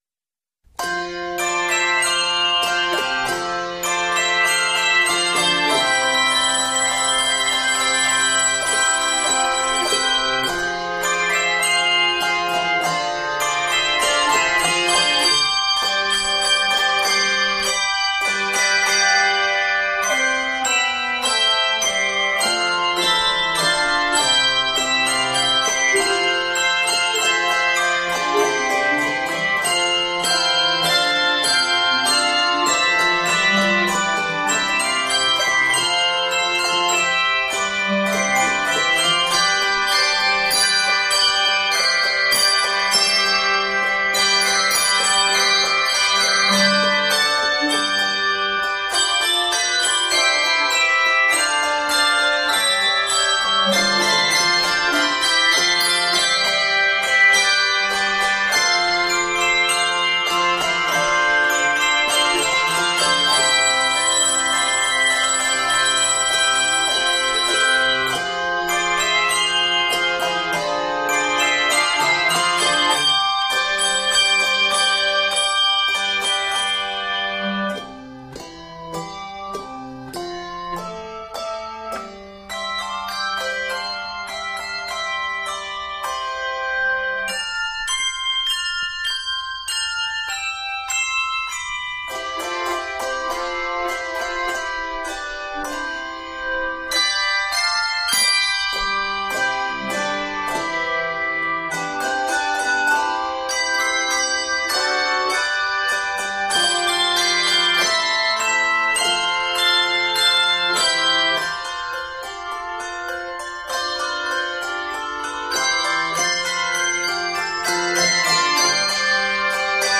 lively and majestic new arrangement